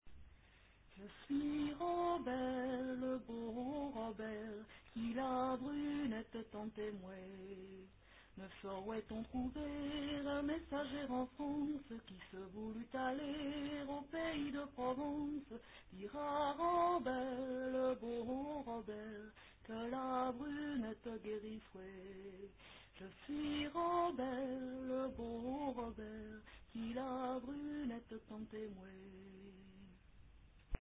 (dernier couplet ;
voix seule, extraite de la version à quatre voix)